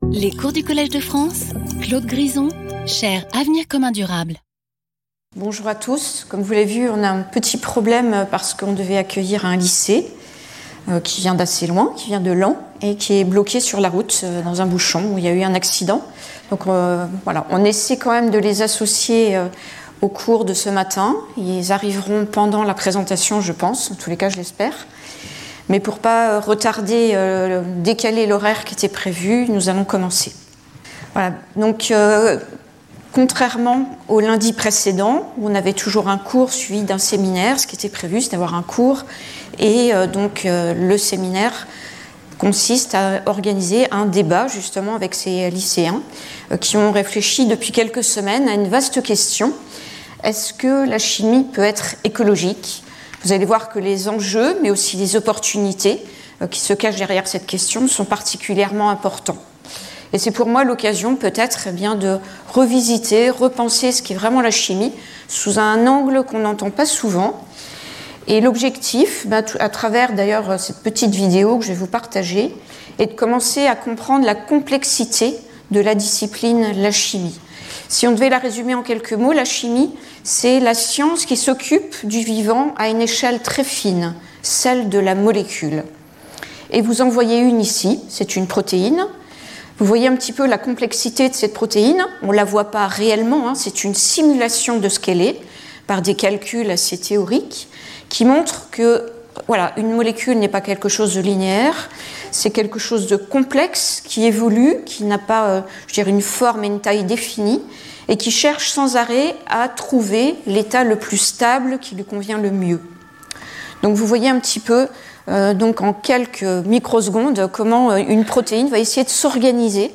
Ce cours sera suivi d’un débat avec des lycéens de Laon qui viendront discuter de ce vaste sujet.